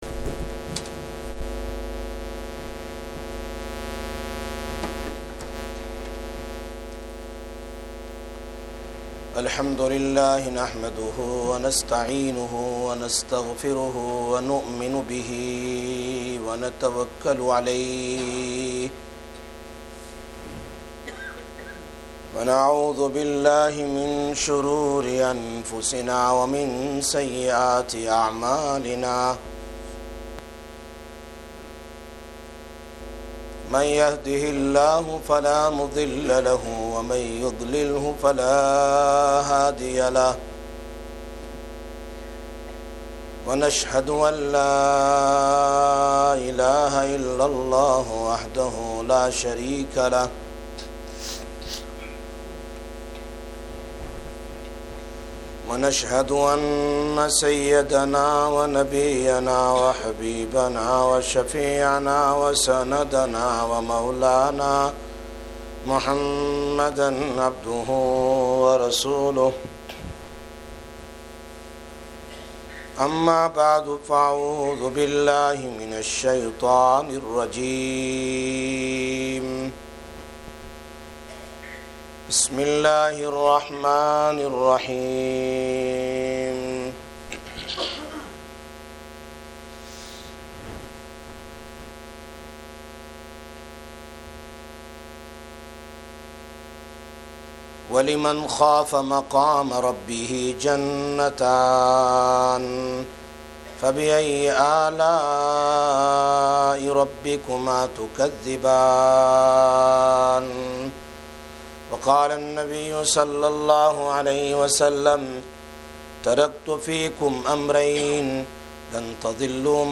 05/08/16 – Jummah Bayan, Masjid Quba